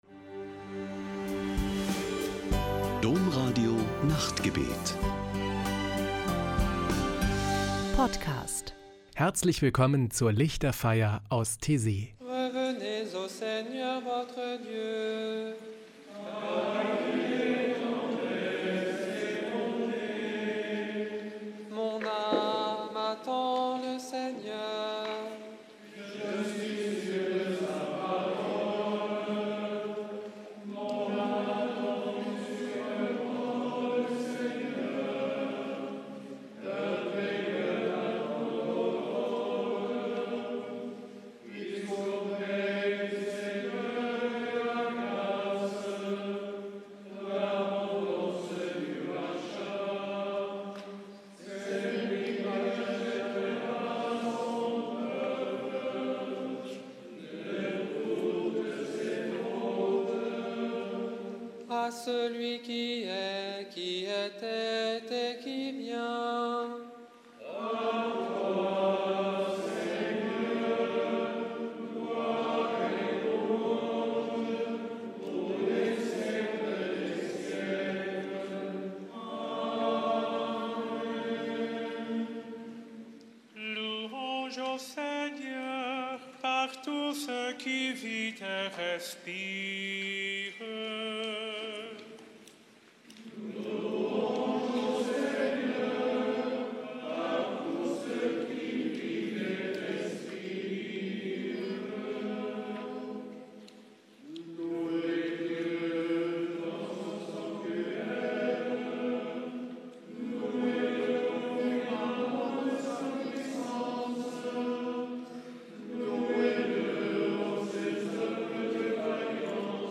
Taizé, ein kleiner Ort im französischen Burgund, steht für Spiritualität und Ökumene.
Ein Höhepunkt jede Woche ist am Samstagabend die Lichterfeier mit meditativen Gesängen und Gebeten.